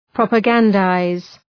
Προφορά
{,prɒpə’gændaız}